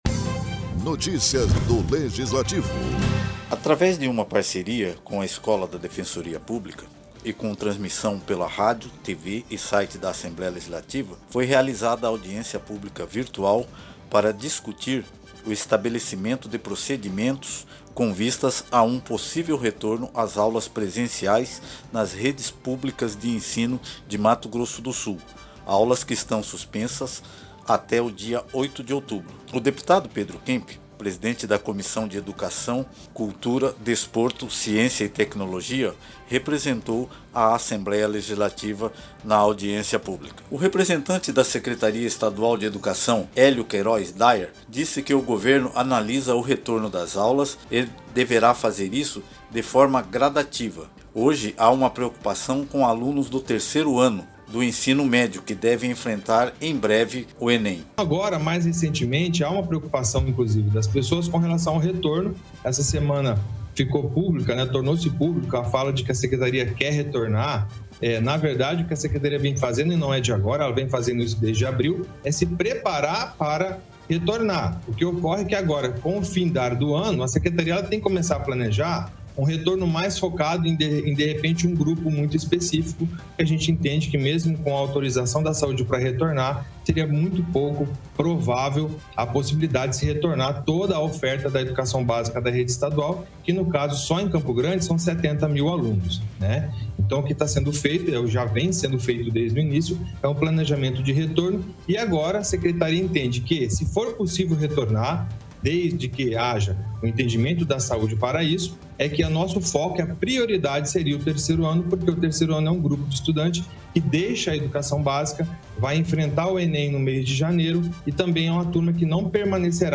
Em parceria com a Escola de Defensoria Pública com transmissão pelo rádio, TV e Site da Assembléia Legislativa foi realizada a audiência publica virtual para discutir o estabelecimento de procedimentos com vistas a um possível retorno às aulas presenciais nas redes públicas de ensino que estão suspensas até o dia 8 de outubro.